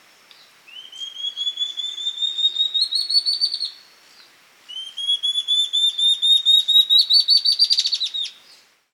Hormiguero Ocelado (Phaenostictus mcleannani)
geo.locationCosta Rica
Hormiguero Ocelado.mp3